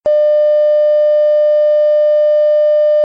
TV Off Air
tv_off_air.mp3